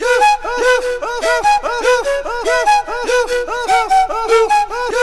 AFRIK FLUTE7.wav